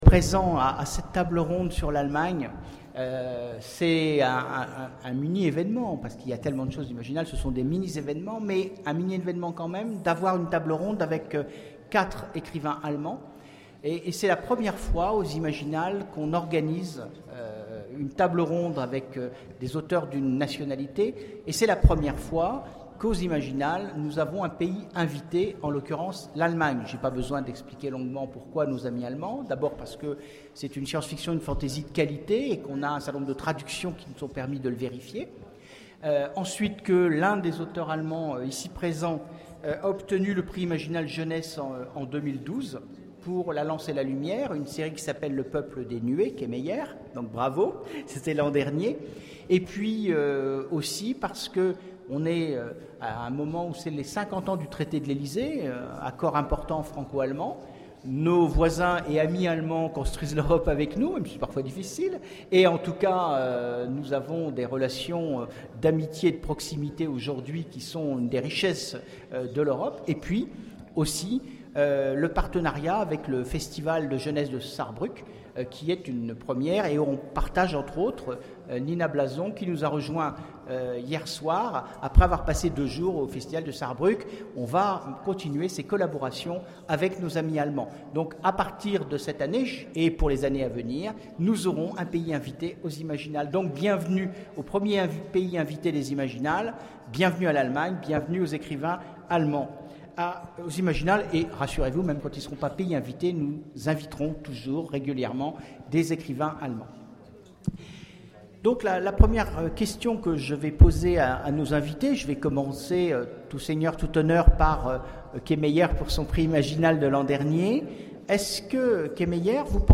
Imaginales 2013 : Conférence Allemagne...
- le 31/10/2017 Partager Commenter Imaginales 2013 : Conférence Allemagne...